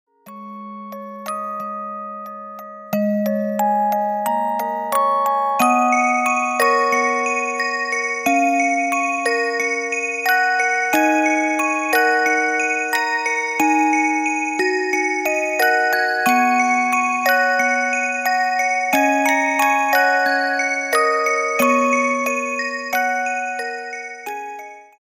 спокойные
инструментальные
колокольчики